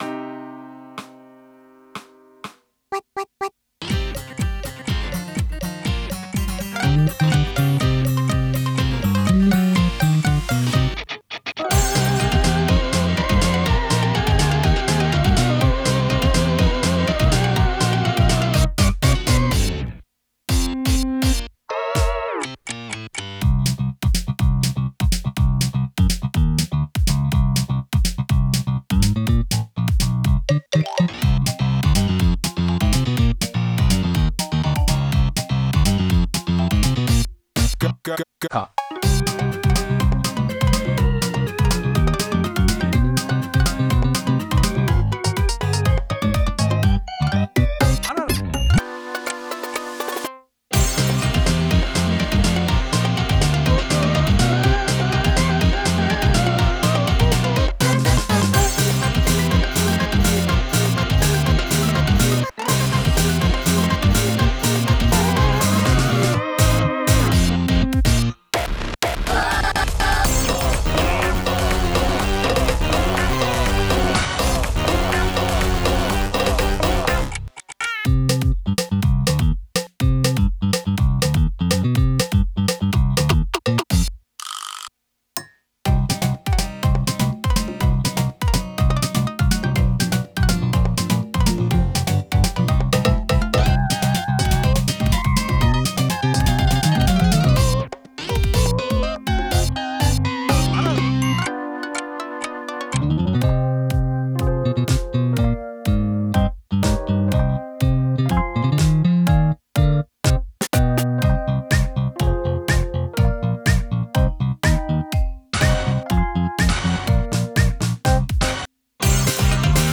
inst（カラオケ）素材など
音源（BPMは123）